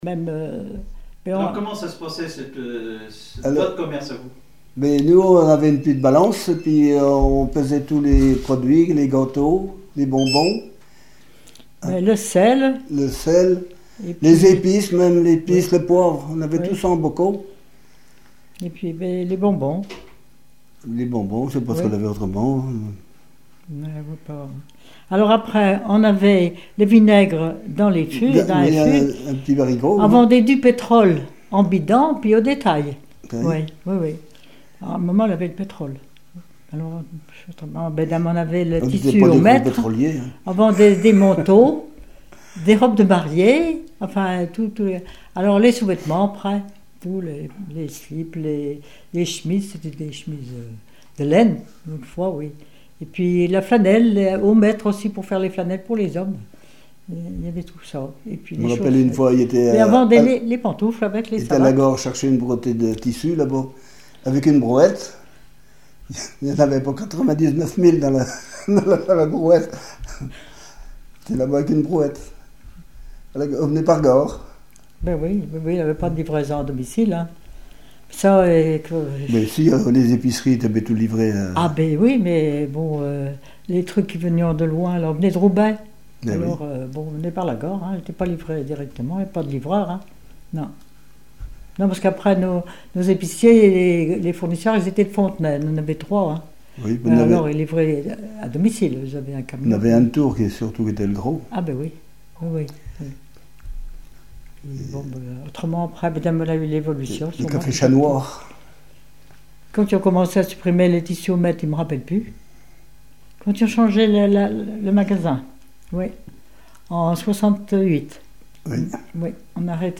Témoignage sur un commerce